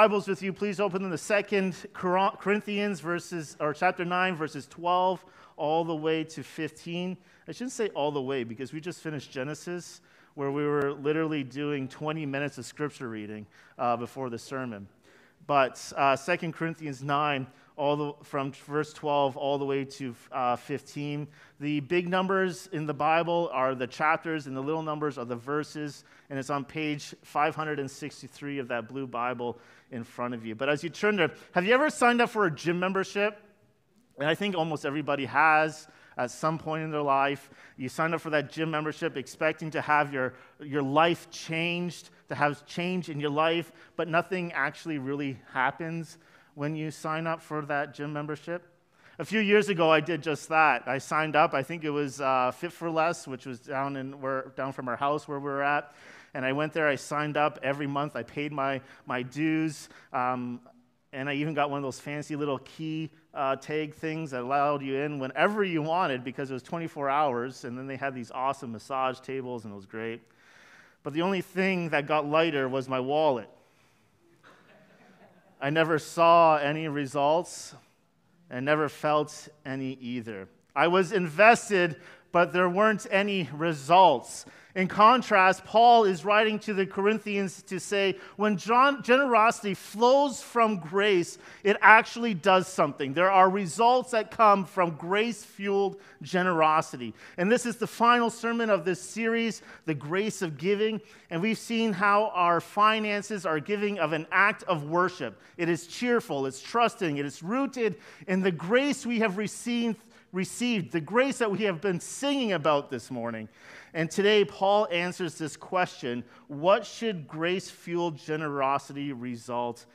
The Result of Grace-Fuelled Giving | 2 Corinthians 9:12-15 | Knollwood Baptist Church